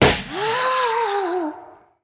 balloon_ghost_pop_04.ogg